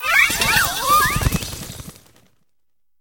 Cri de Cléopsytra dans Pokémon HOME.